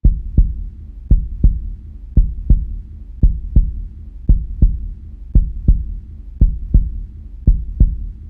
HeartbeatHide.mp3